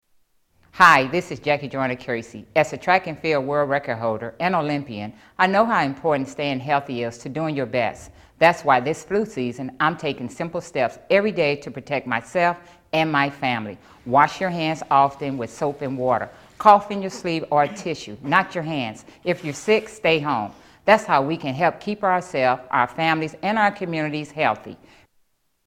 Tags: Media Flu PSA's Flu Public Service Announcements H1N1